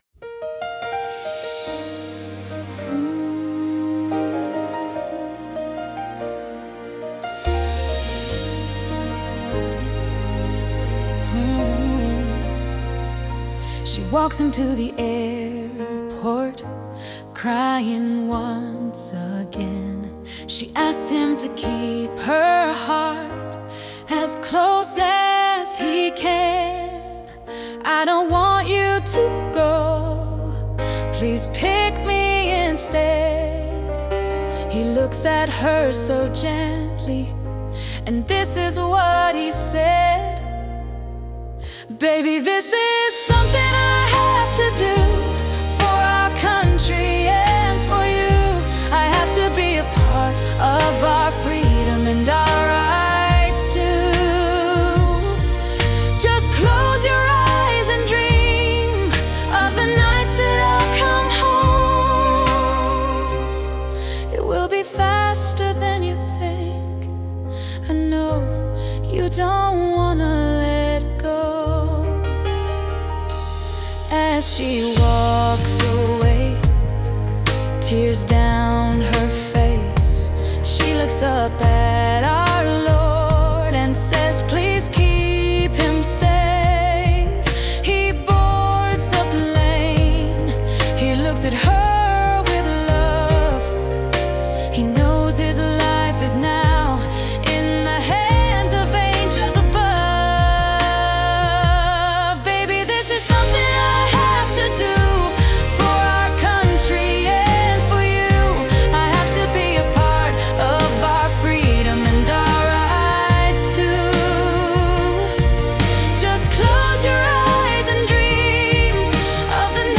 Category: R&B